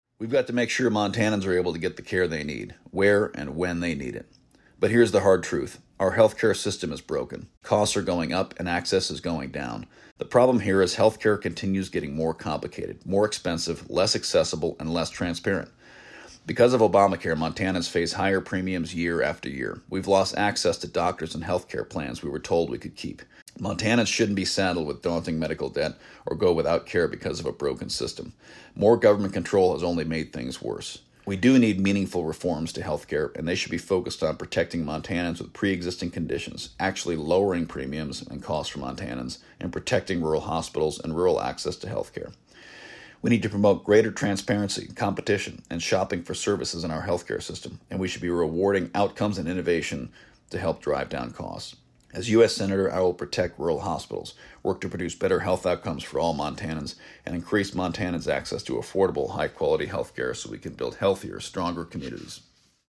The candidates' responses were aired on Voices of Montana, October 9-11, 2024.